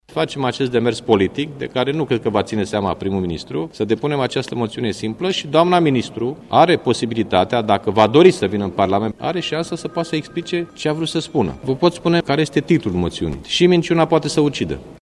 Potrivit liderului PSD, Liviu Dragnea, declaraţiile ministrului justiţiei sunt foarte grave: